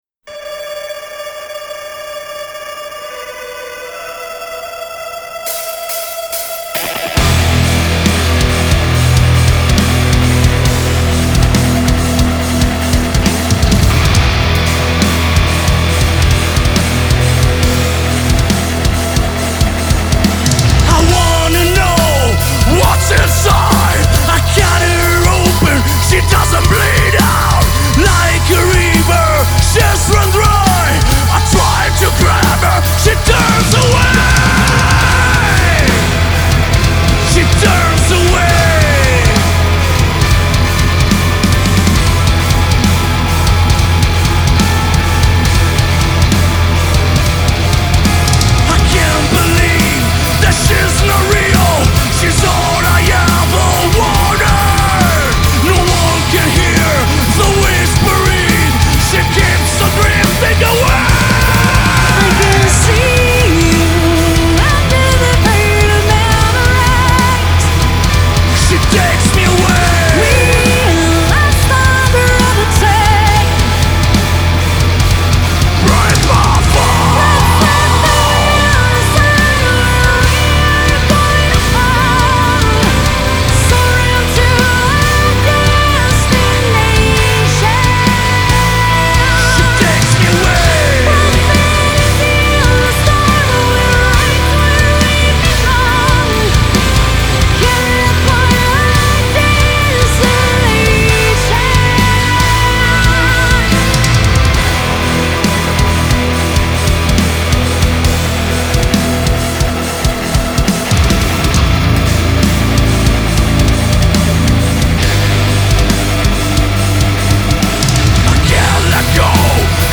Genre : Rock, Hard Rock, Metal